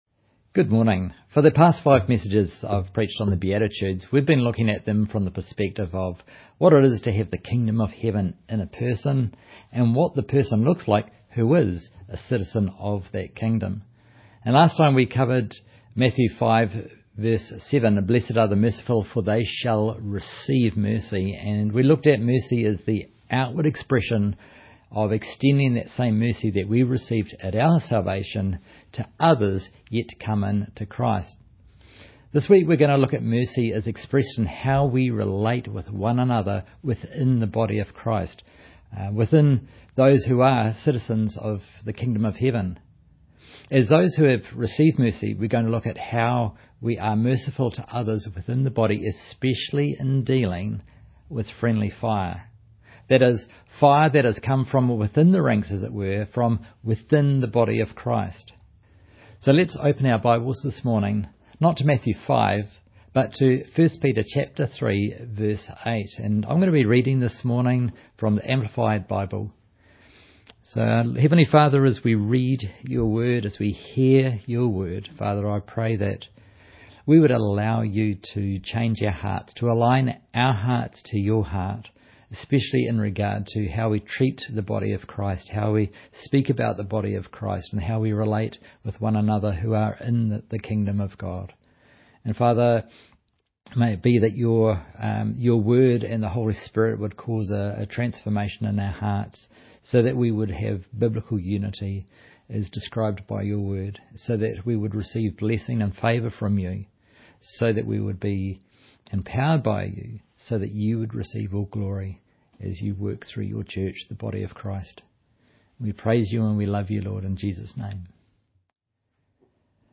Online Sunday Morning Service20th April, 2025 […]